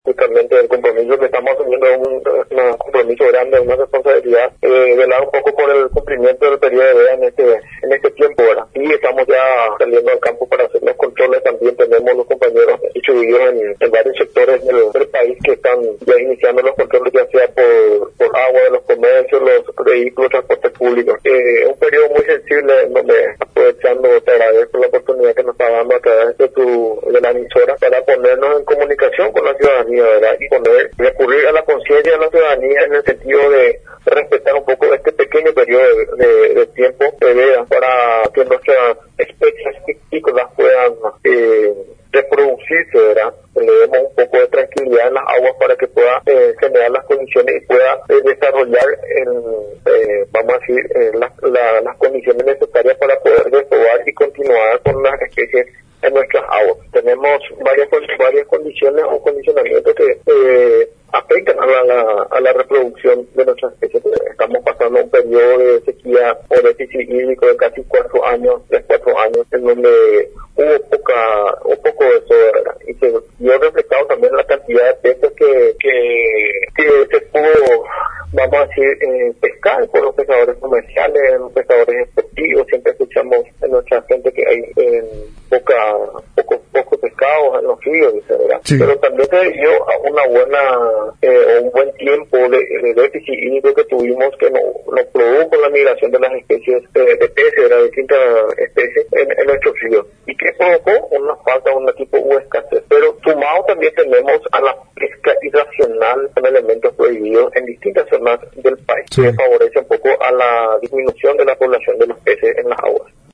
El Ing. Adán Leguizamón, director de Pesca y Acuicultura de la SEAM, anunció sobre los estrictos controles a llevarse adelante mediante la verificación y fiscalización en las rutas, comercios, transportes y vías fluviales, esto con el objetivo de cumplir las normativas ambientales.